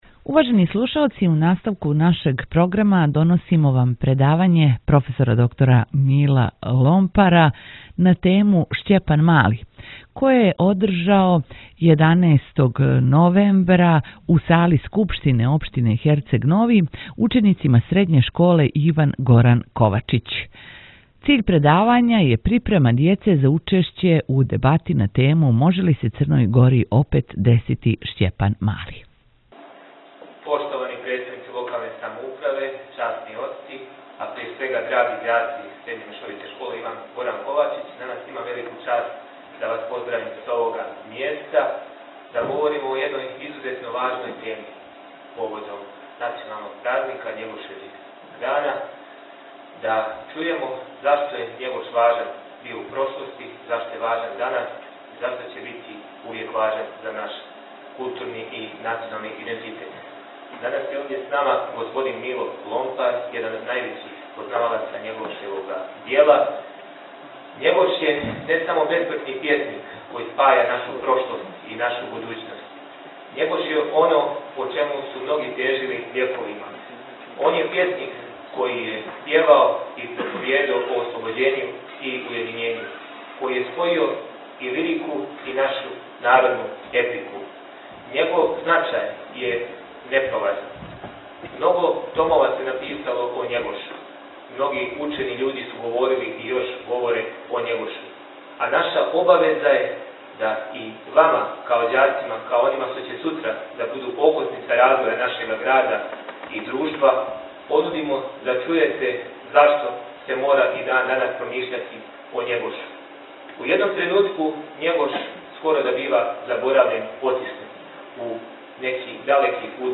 У оквиру програма обиљежавања Његошевог дана, црногорског празника културе, данас је у сали Скупштине општине Херцег Нови уприличено предавање проф. […]